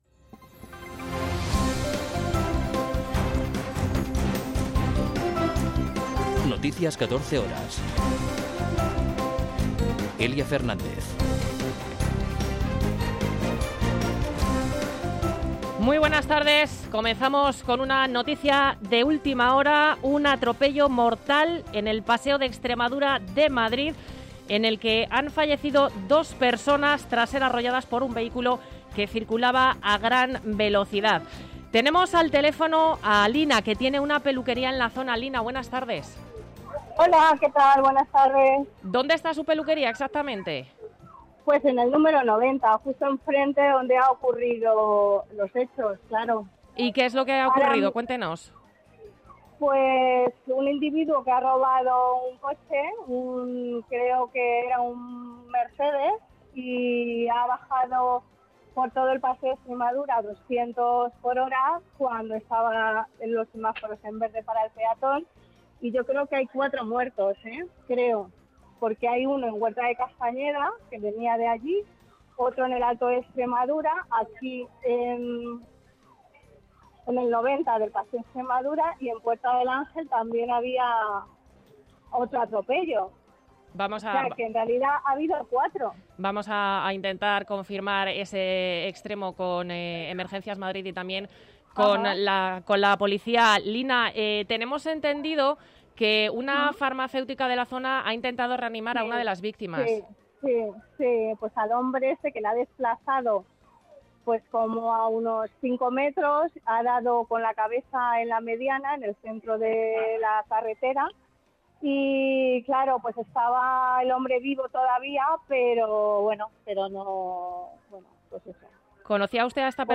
Noticias 14 horas 27.04.2023